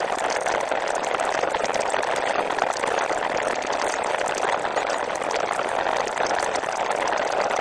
描述：星际，世界，声音设计
标签： 星际 音响设计 世界
声道立体声